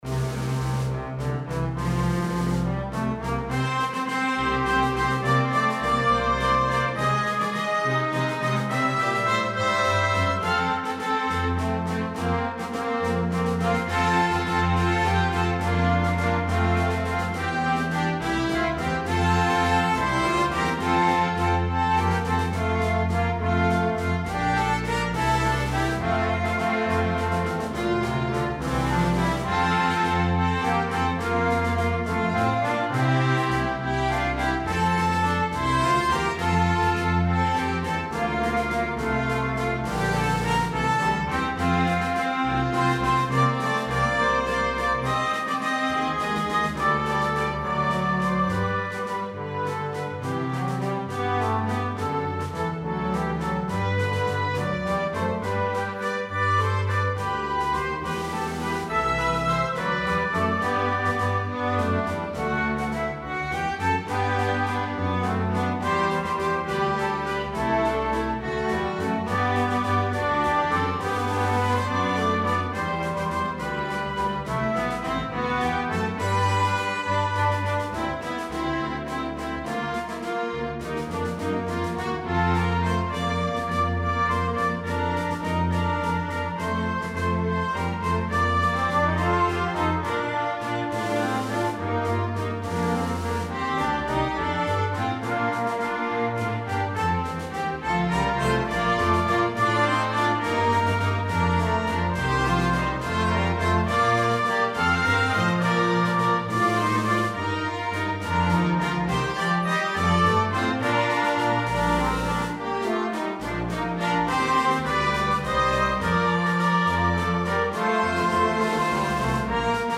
A joyful and enthusiastic arrangement of this hymn.
Arranged in 4 parts, fully orchestrated.